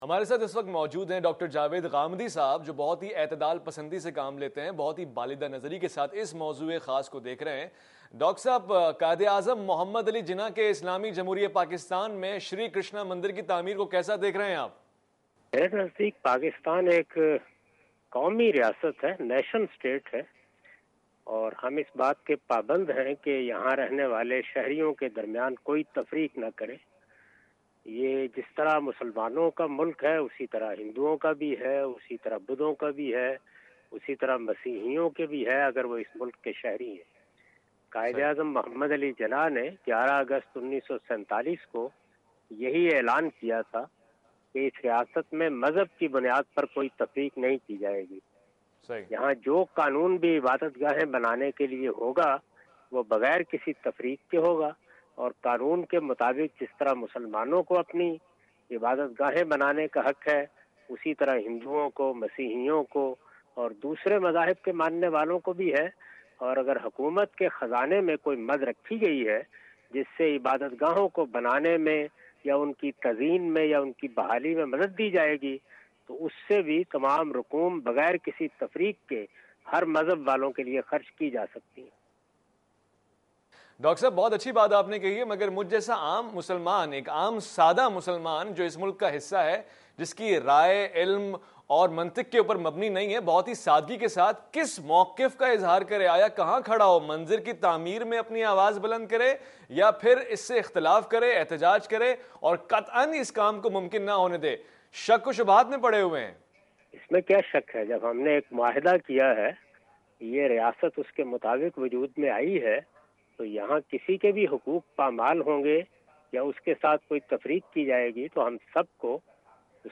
Category: TV Programs / Questions_Answers /
Javed Ahmad Ghamidi answers some important question about Construction of a Hindu Temple in Pakistan.